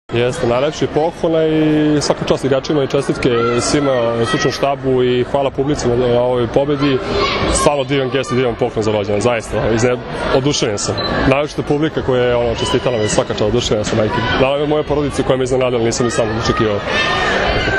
IZJAVA ALEKSANDRA OKOLIĆA